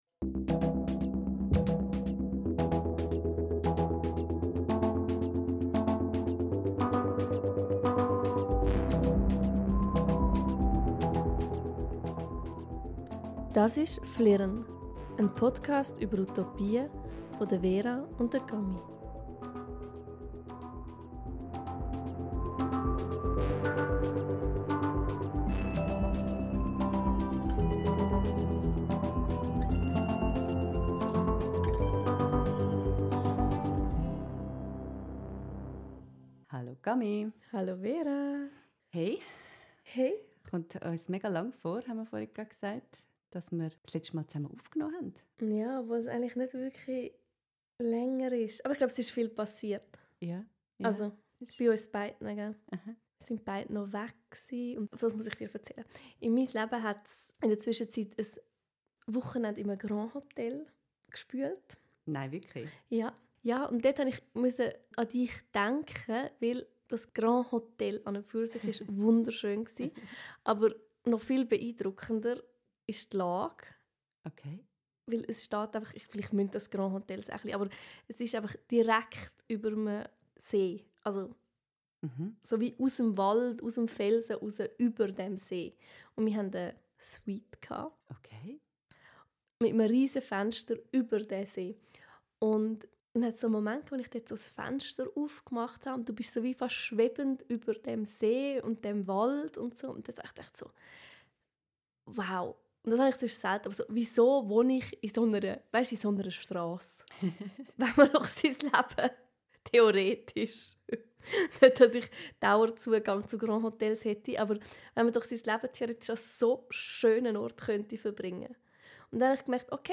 Mutig - und vielleicht etwas naiv?! - legen wir einen weiteren Riesenbegriff zwischen uns auf den Kaffeetisch: Wissen! Sogleich entbrennt eine lebendige Debatte, die sich hauptsächlich im Feld der Medizin abspielt.